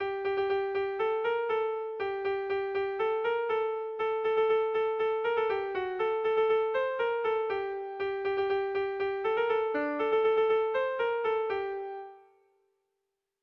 Air de bertsos - Voir fiche   Pour savoir plus sur cette section
AABAB